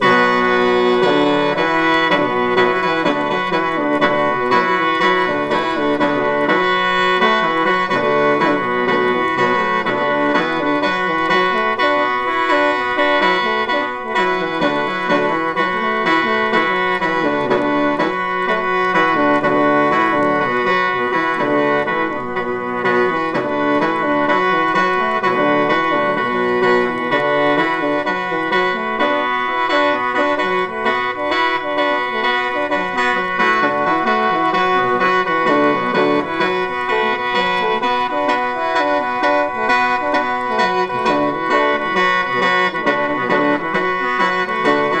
World, Folk, Filed Recording　France　12inchレコード　33rpm　Stereo